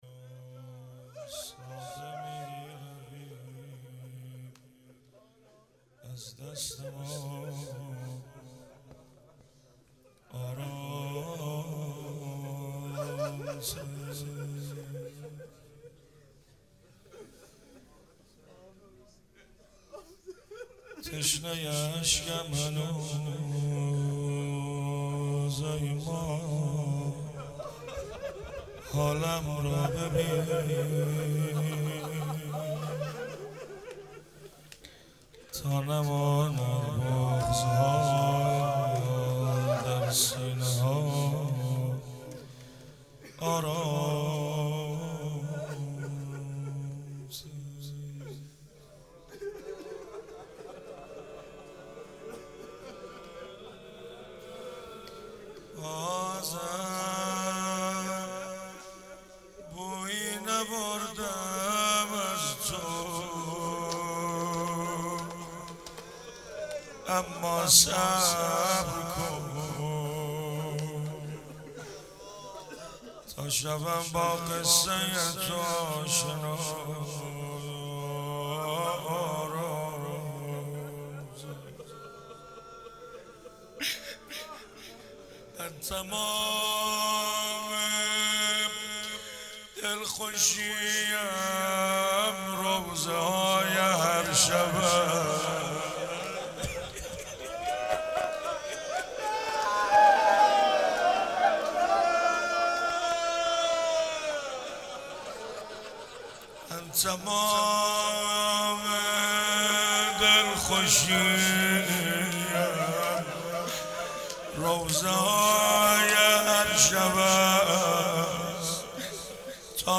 مراسم 30 محرم الحرام 94 :: هیئت علمدار
روضه
شور